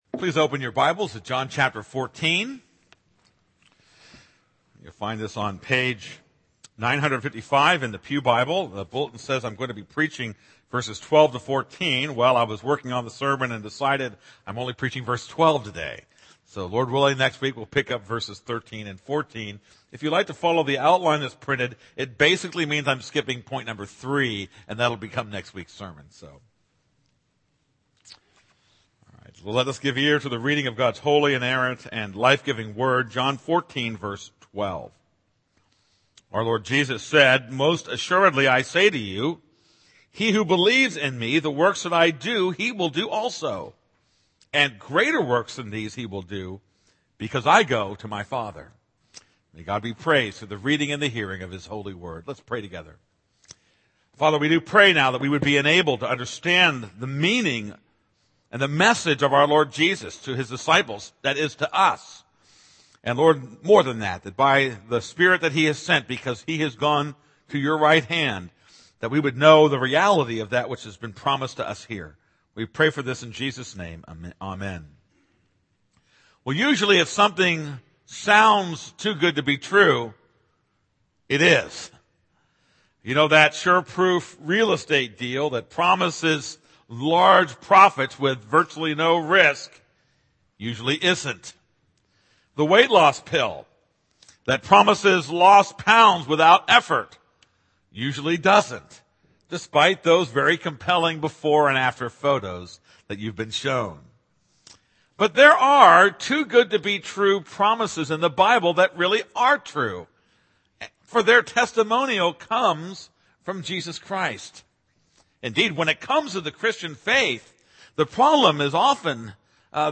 This is a sermon on John 14:12-14.